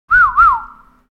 알림음(효과음) + 벨소리
알림음 8_Whistling_2.mp3